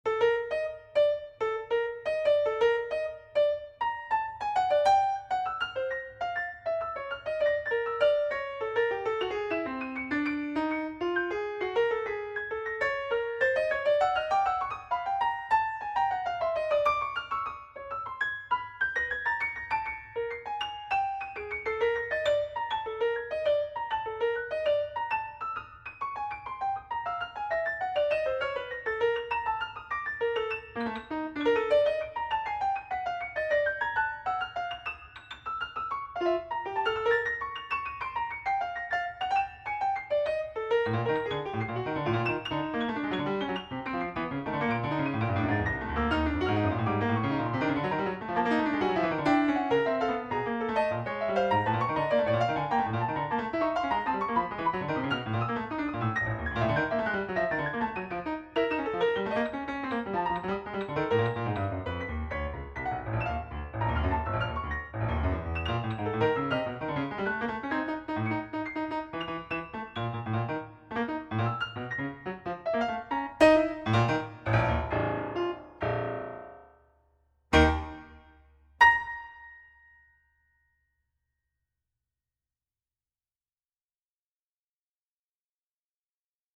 Yamaha Disklavier